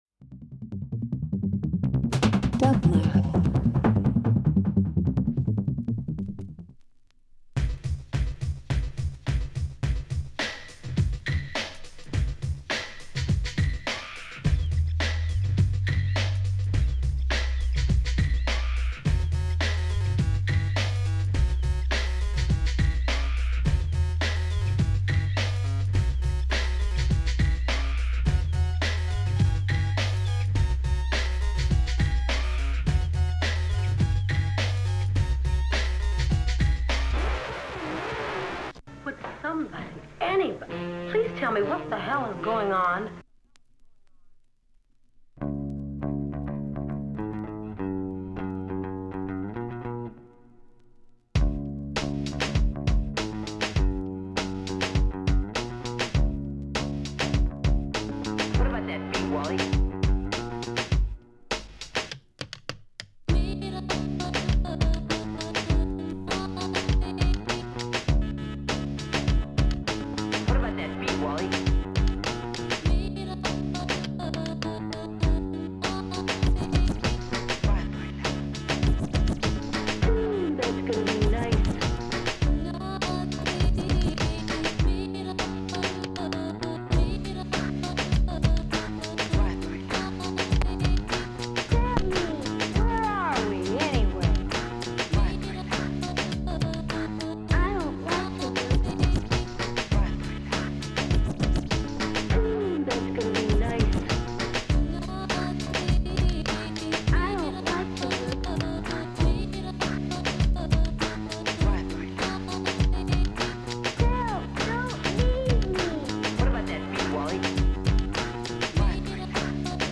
Alternative Dance Electronic House